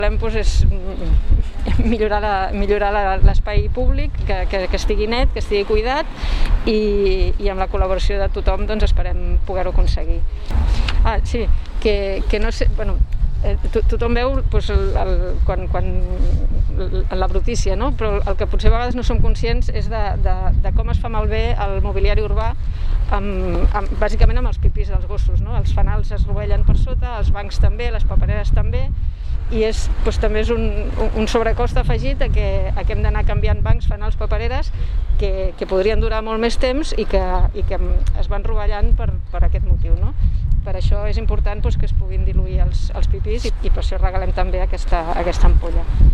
-Declaracions d'Eulàlia Mimó